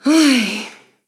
Reproche de una mujer: ¡Ay!
exclamación
interjección
mujer
resignación
Sonidos: Voz humana